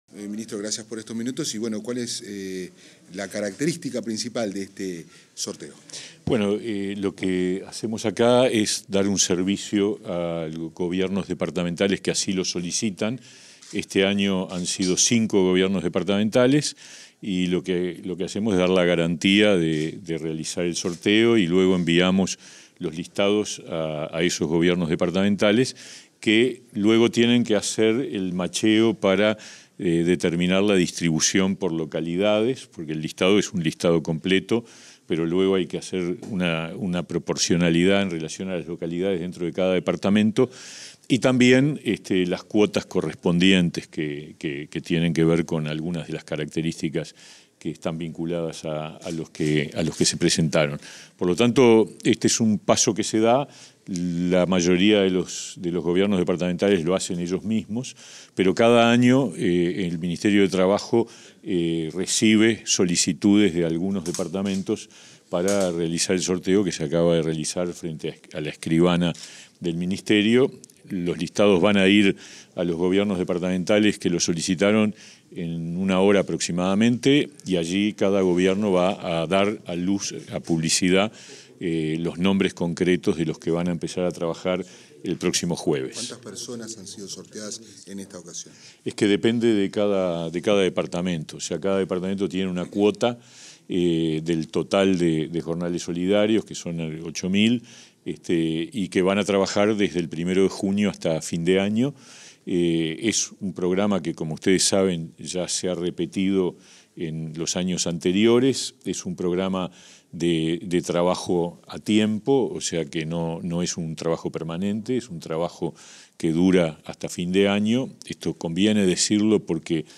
Declaraciones del ministro de Trabajo y Seguridad Social, Pablo Mieres
Declaraciones del ministro de Trabajo y Seguridad Social, Pablo Mieres 30/05/2023 Compartir Facebook X Copiar enlace WhatsApp LinkedIn Tras el sorteo de nuevos cupos del programa Oportunidad Laboral para Colonia, Flores, Lavalleja y Rivera, este 30 de mayo, el ministro de Trabajo y Seguridad Social, Pablo Mieres, realizó declaraciones a la prensa.